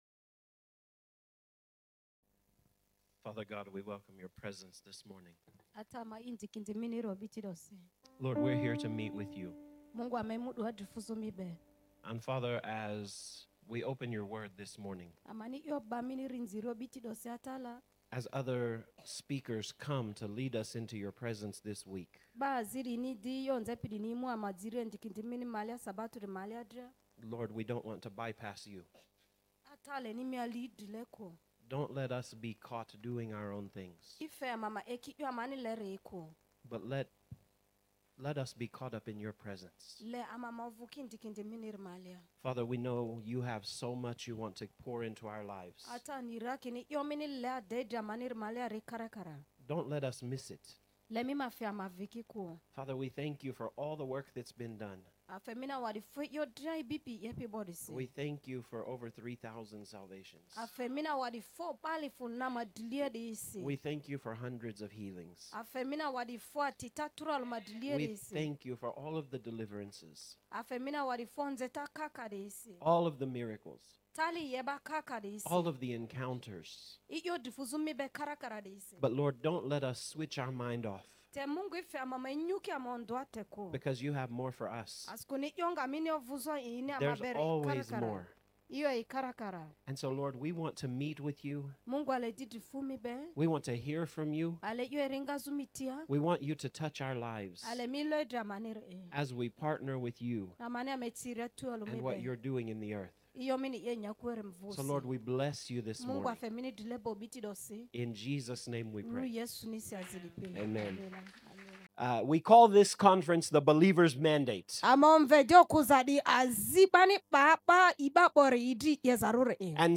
Sermons | Sozo Ministries